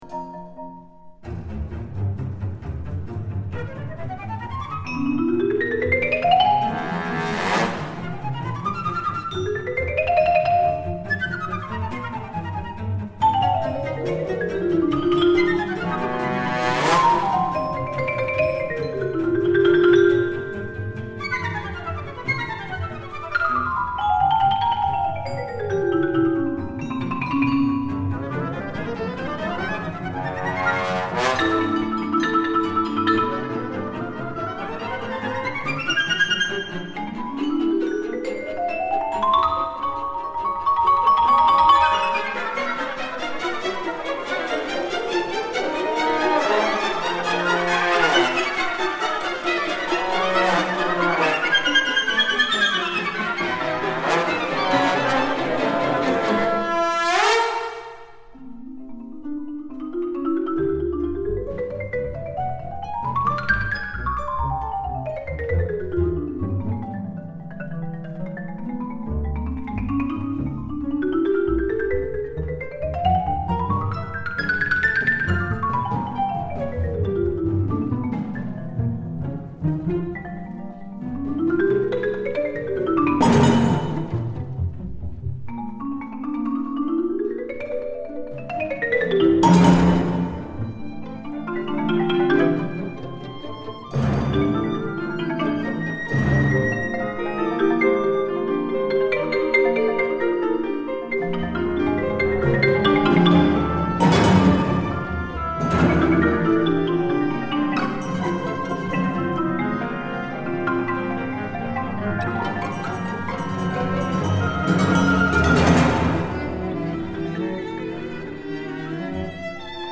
A Fantasy for marimba and Orchestra 2004